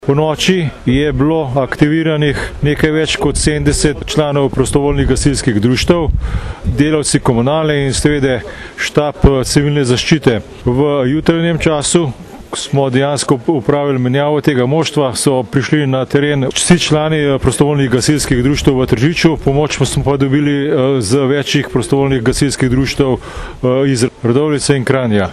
izjava_alesprijondrzavnisekretarnaministrstvuzaokolje.mp3 (1,1MB)